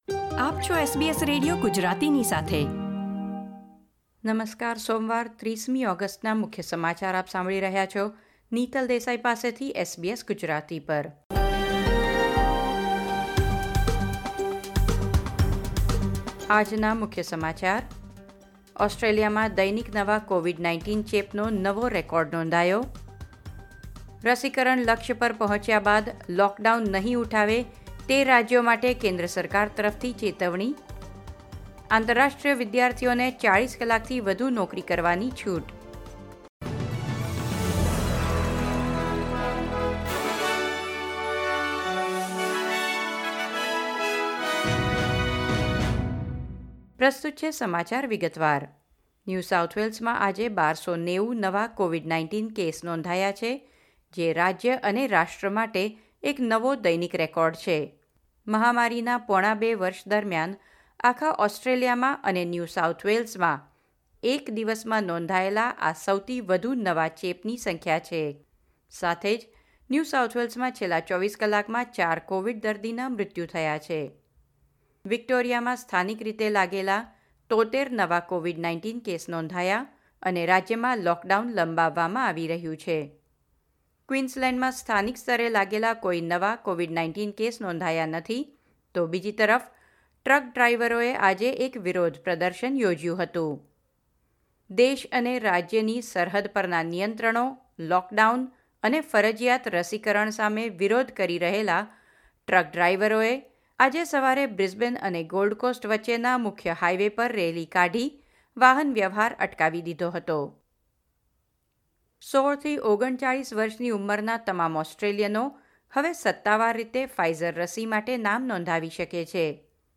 SBS Gujarati News Bulletin 30 August 2021